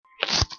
Weird Walking Noise Sound Button - Free Download & Play